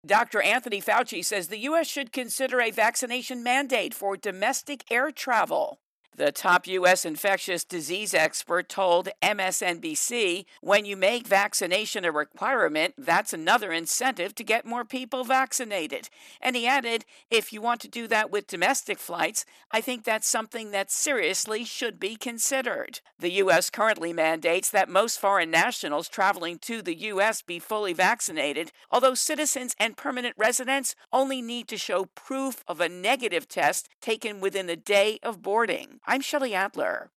Fauci intro and voicer